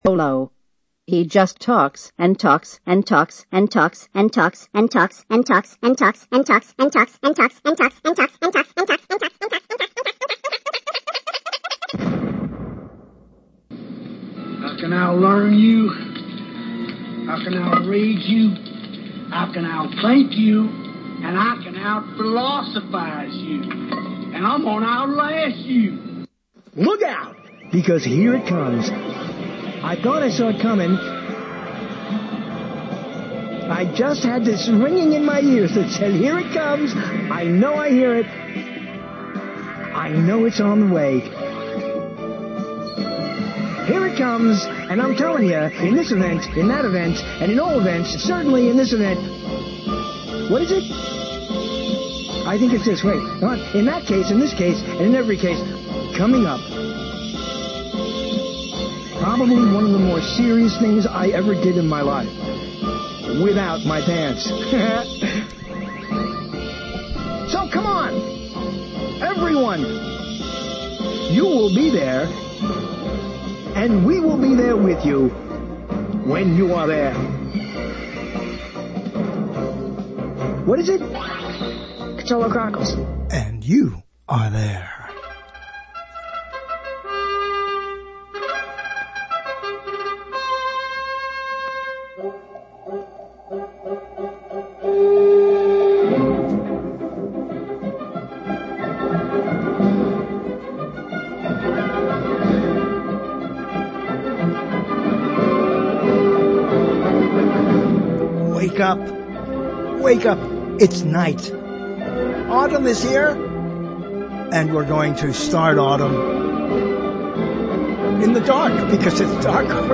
Listen live on the original Internet talk broadcast.